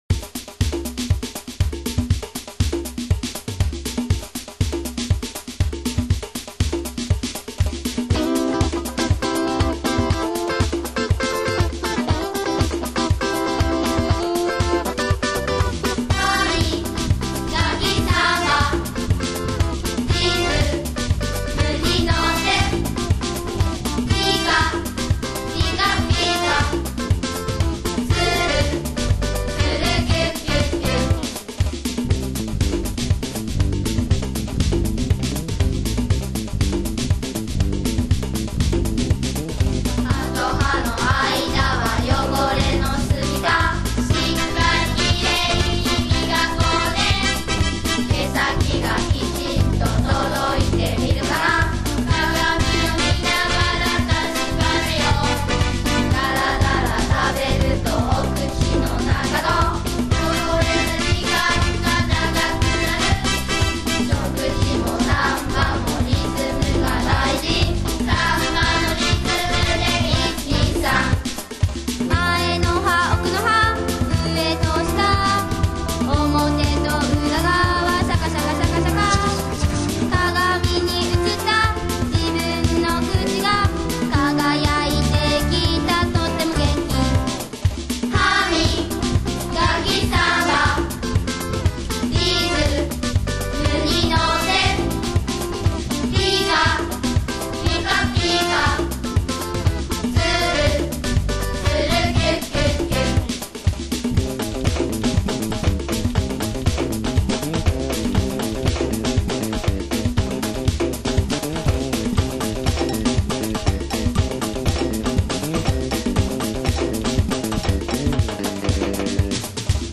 The song was sung by a group of sixth graders from Shimizu Elementary School in Fukushima City.
Brushing teeth after each meal to the rhythm of the samba might be the ultimate way to fight cavities!